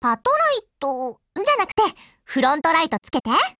贡献 ） 协议：Copyright，其他分类： 分类:语音 、 分类:少女前线:P2000 您不可以覆盖此文件。
P2000_GOATTACK_JP.wav